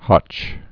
(hŏch)